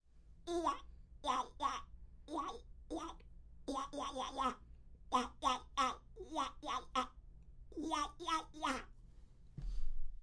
描述：模仿鸭子或企鹅中间速度
Tag: 企鹅